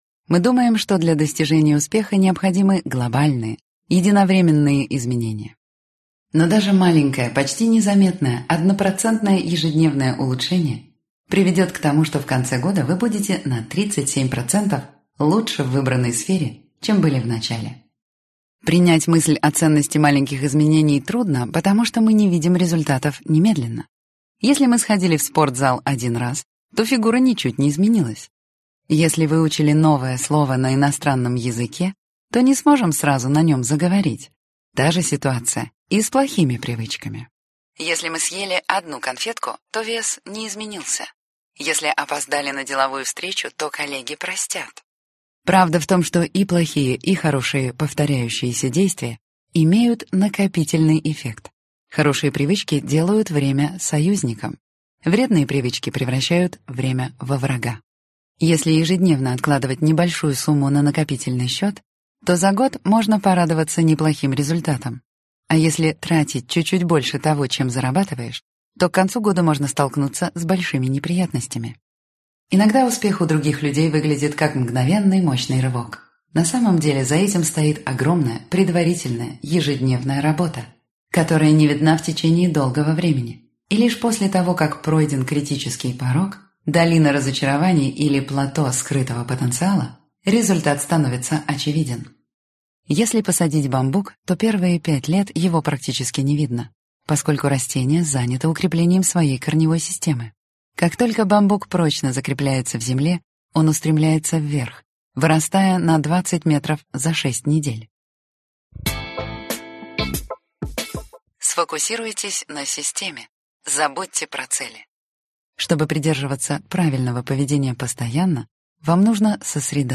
Аудиокнига Год полезных привычек. Полный курс знаний, чтобы приобрести привычки, важные для благополучия и счастья | Библиотека аудиокниг